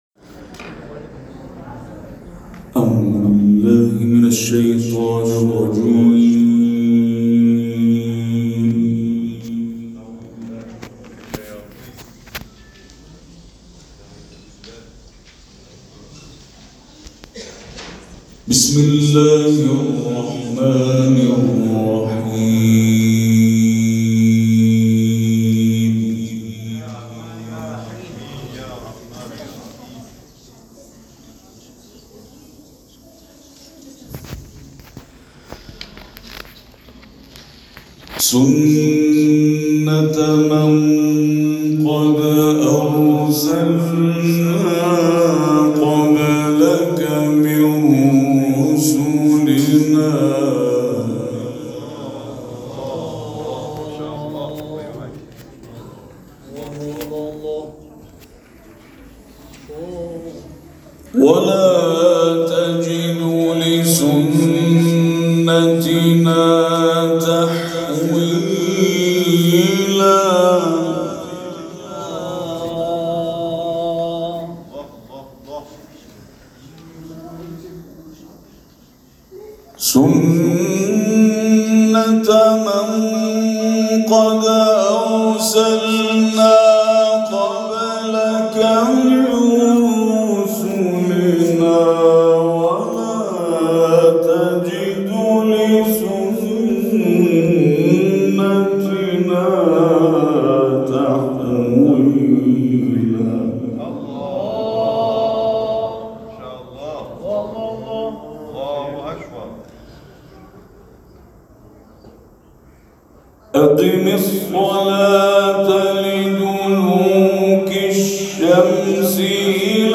قاری بین‌المللی کشورمان، روز گذشته، ششم خرداد با حضور در محفل قرآنی آستان امامزاده حیدر(ع) کلاک آیاتی از سوره اسراء و سوره طارق را تلاوت کرد.